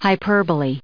The right pronunciation of “hyperbole” is as follows:
UK /haɪˈpɜː.bəl.i/ US /haɪˈpɝː.bəl.i/